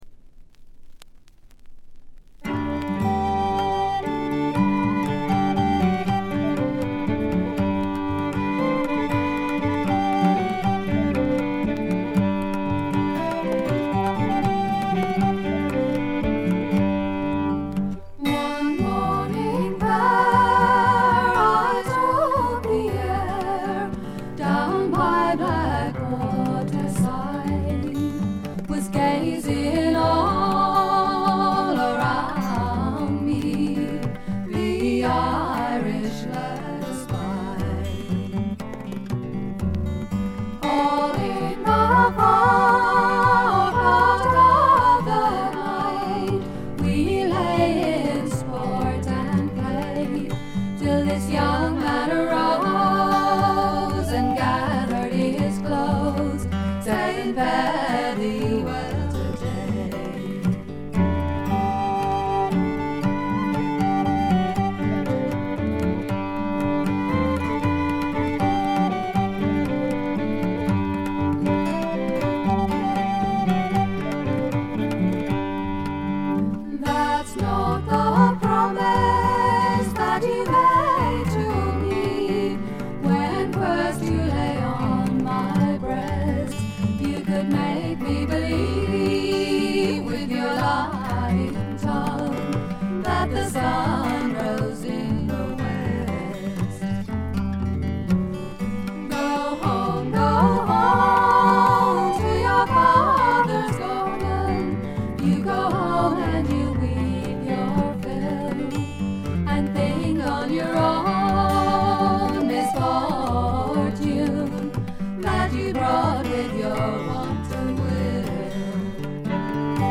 バックグラウンドノイズや軽微なチリプチは普通レベルで出ますが特筆するようなノイズはありません。
また専任のタブラ奏者がいるのも驚きで、全編に鳴り響くタブラの音色が得も言われぬ独特の味わいを醸しだしています。
試聴曲は現品からの取り込み音源です。
tabla, finger cymbals